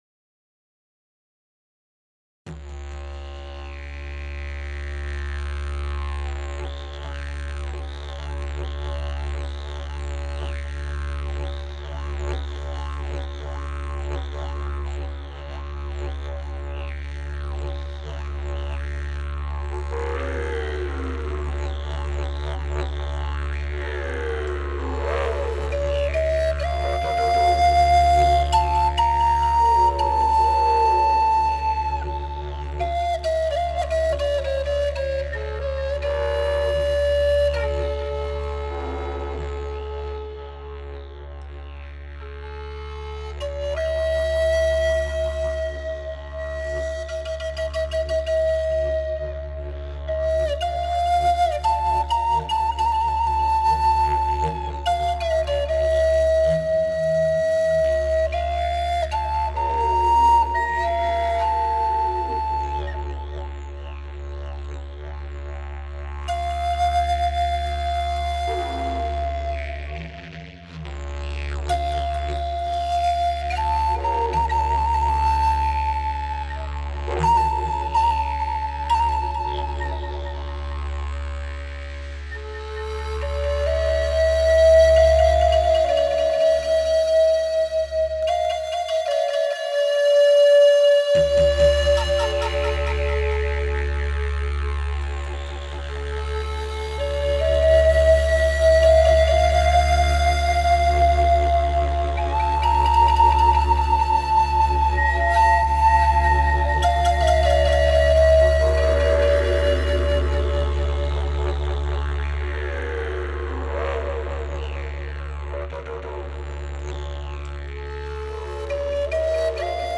Podczas otwarcia nowego studia tańca BODYMYSTIC, miałem przyjemność wystąpić z wyjątkowym repertuarem utworów, które zaprezentowałem publiczności. Moja muzyka, inspirowana kulturami świata i naturą, miała na celu stworzenie niepowtarzalnej atmosfery, która harmonizuje z ruchem i ekspresją taneczną. Wśród utworów, które wykonałem, znalazły się te stworzone z wykorzystaniem moich ulubionych instrumentów – fletów indiańskich oraz didgeridoo.